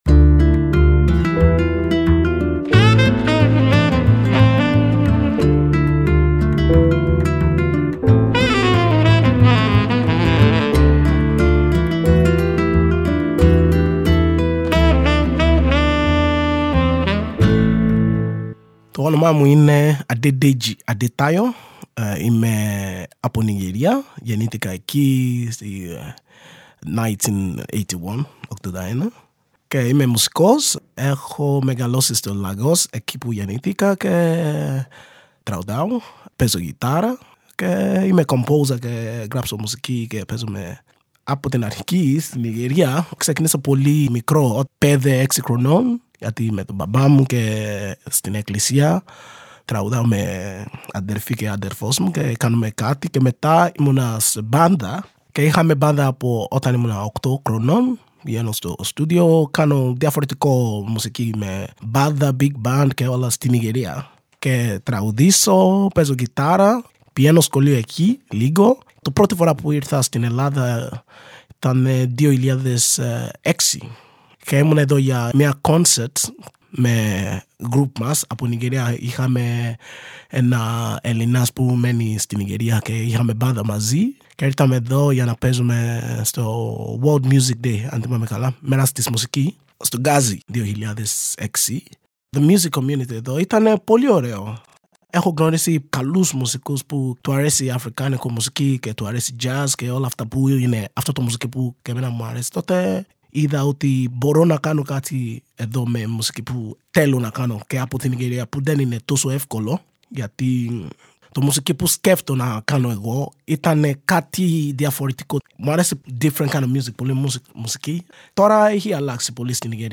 Στο πλαίσιο της συνεργασίας του Μουσείου Μπενάκη με την ΕΡΤ και το Κosmos 93,6, ηχογραφήθηκε, ειδικά για την έκθεση, μια σειρά προσωπικών αφηγήσεων μερικών από τους μουσικούς που συμμετέχουν στο ηχοτοπίο ΗΧΗΤΙΚΕΣ ΔΙΑΣΤΑΣΕΙΣ ΑΦΡΙΚΑΝΙΚΗΣ ΔΙΑΣΠΟΡΑΣ.
Τις συνεντεύξεις πήραν οι δημοσιογράφοι και παραγωγοί